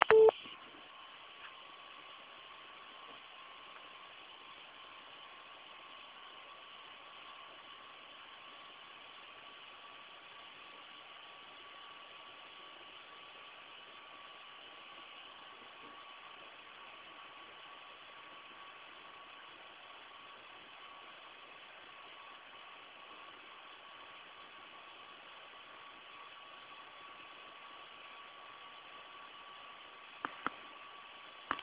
Referencyjny, miedziany radiator z małym coolerem w trakcie pracy w trybie 3D zachowuje się tak jak na tym